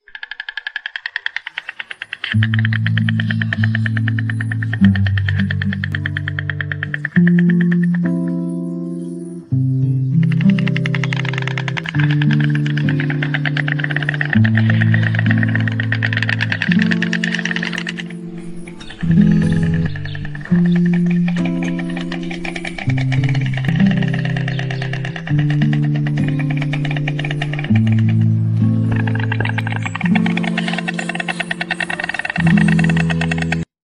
东方白鹳不能鸣叫 靠击喙发出吱吱响声